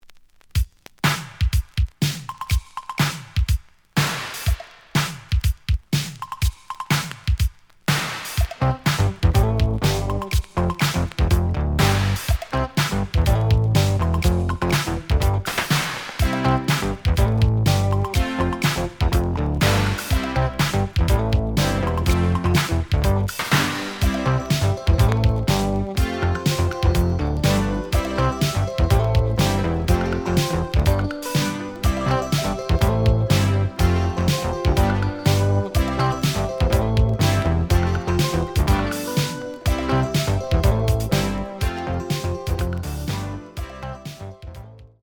(Instrumental - Short Version)
The audio sample is recorded from the actual item.
●Genre: Disco